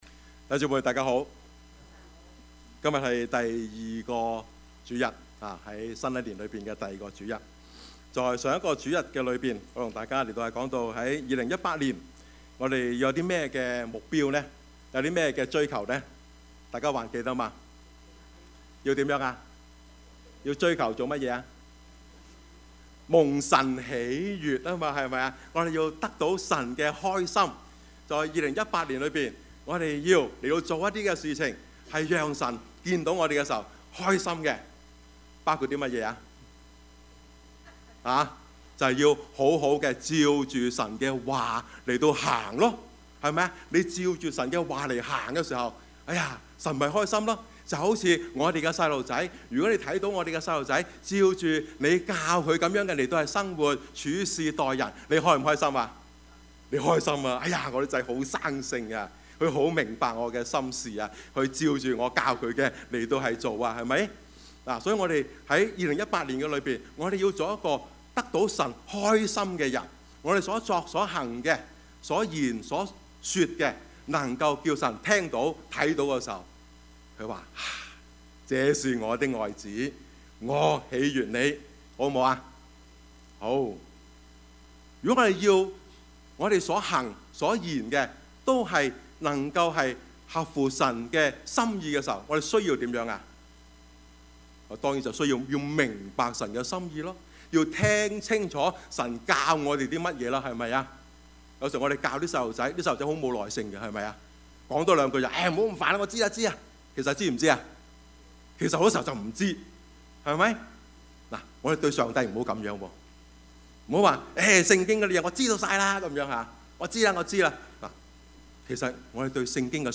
Service Type: 主日崇拜
」 Topics: 主日證道 « 蒙神喜悅 寬恕之道 »